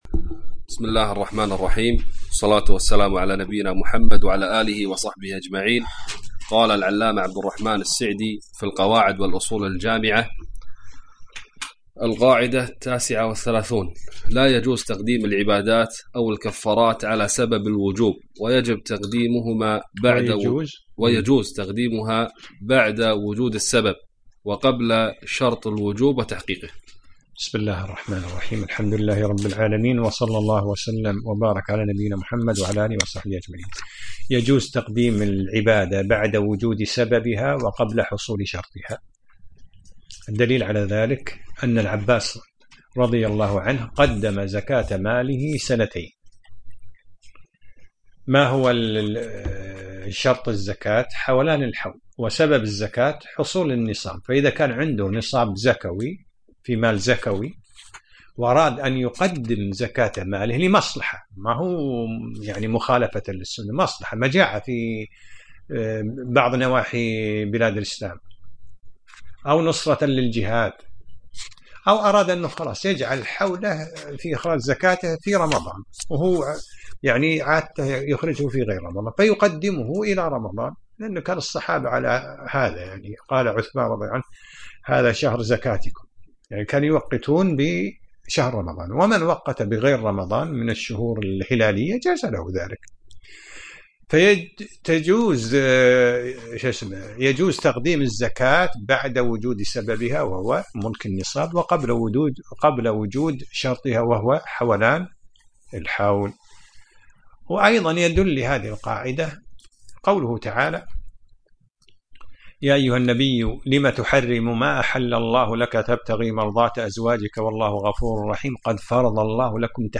الدرس الثاني عشر : من القاعدة 39 إلى القاعدة 41